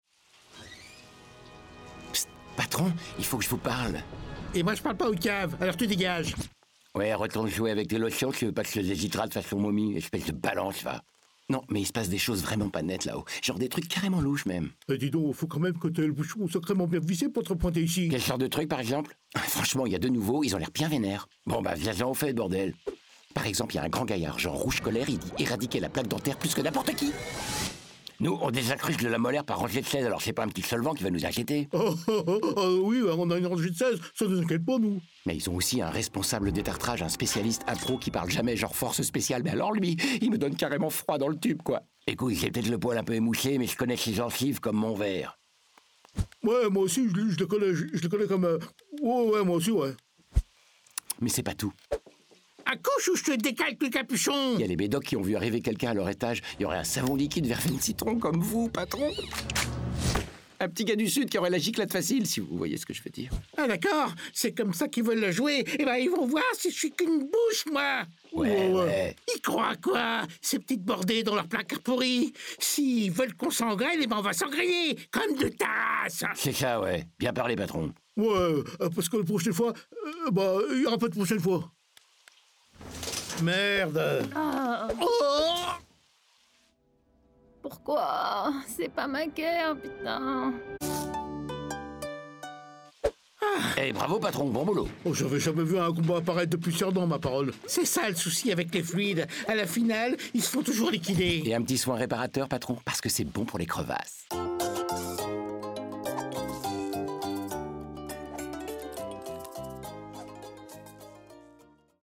Coca-Cola (Publicité)